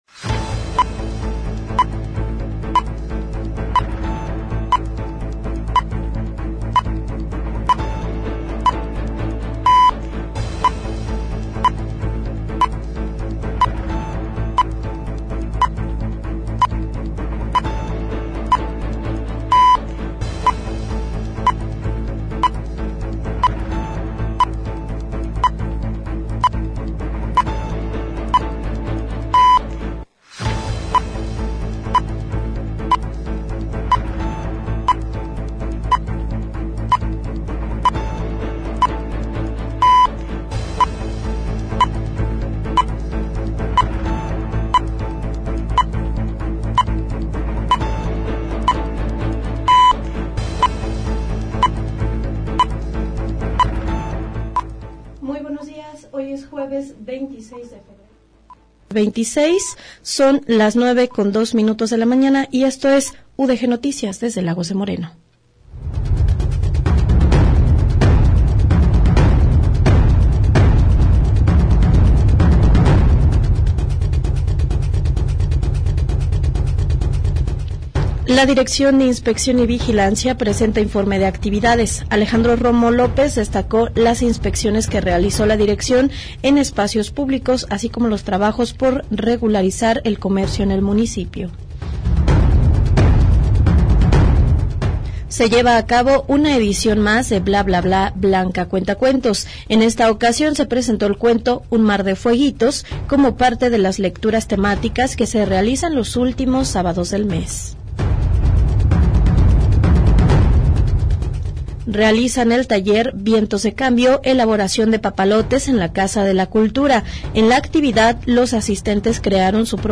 Espacio periodístico dedicado a recopilar, analizar y difundir los acontecimientos más relevantes de una comunidad específica. Ofrece cobertura puntual de los hechos más importantes a nivel local y regional.
GÉNERO: Informativo